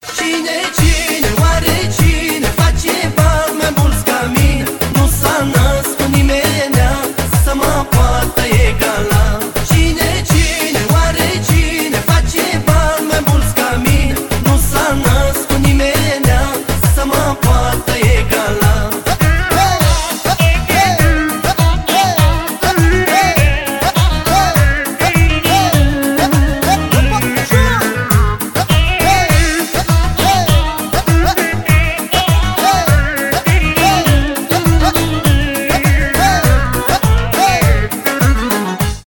поп , зарубежные , танцевальные , румынские